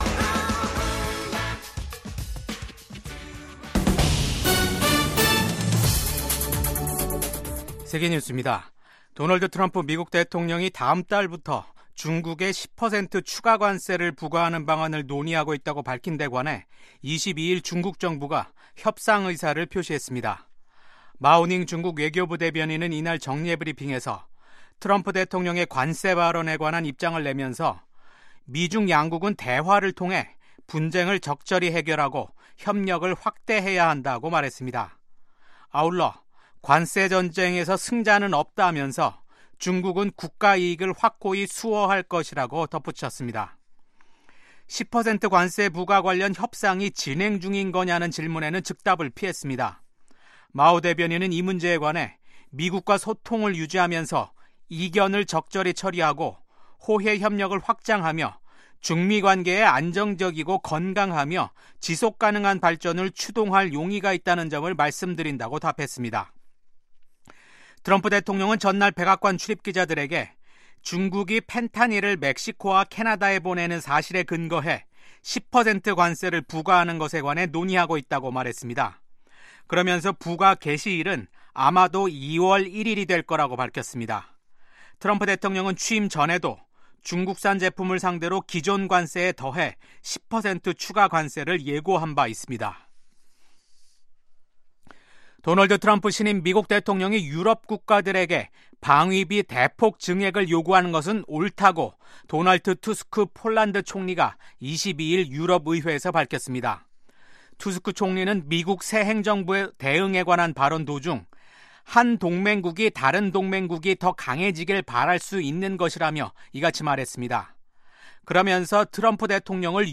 VOA 한국어 아침 뉴스 프로그램 '워싱턴 뉴스 광장'입니다. 미국의 외교·안보 전문가들은 도널드 트럼프 대통령의 ‘북한 핵보유국’ 발언이 북한의 현실을 언급할 것일뿐 핵보유국 지위를 인정하는 것은 아니라고 분석했습니다. 트럼프 대통령의 취임 직후 대북 메시지가 협상 재개를 염두에 둔 유화적 신호에 그치지 않고 김정은 국무위원장을 압박하는 이중적 메시지라는 관측이 나옵니다.